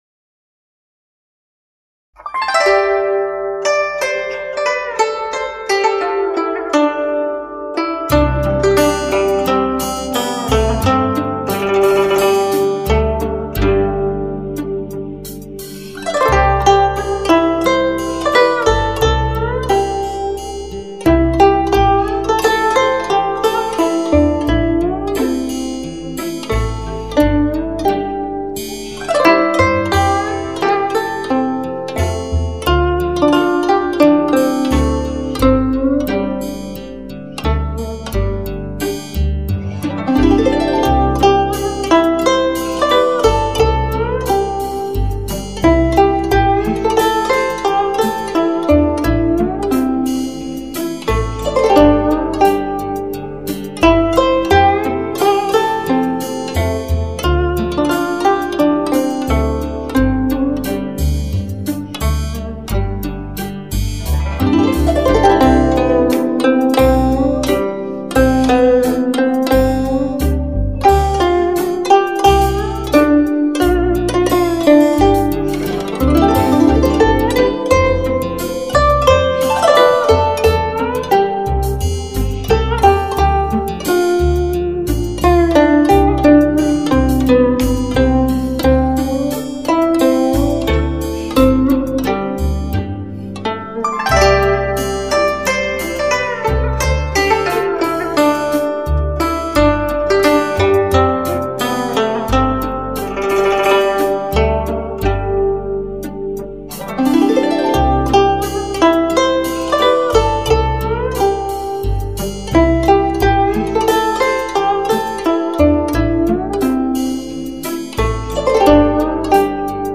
唱片类型：民族音乐
专辑语种：纯音乐